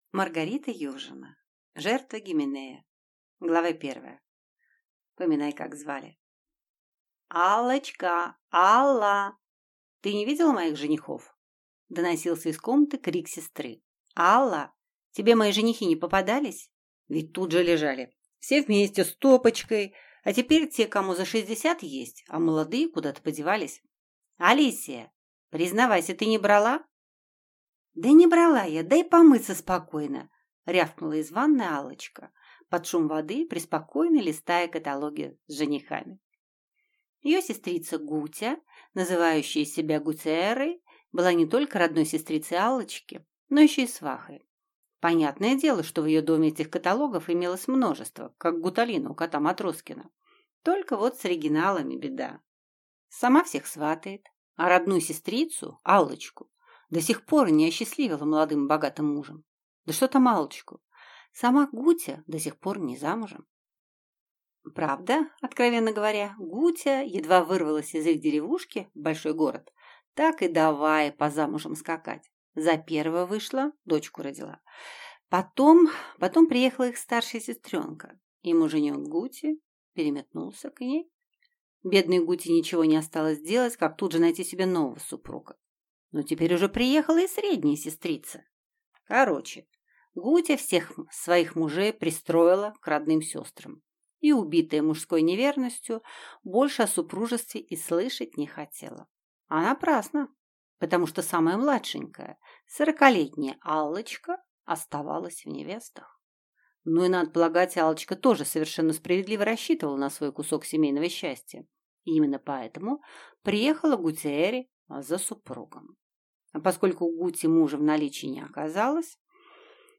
Аудиокнига Жертва Гименея | Библиотека аудиокниг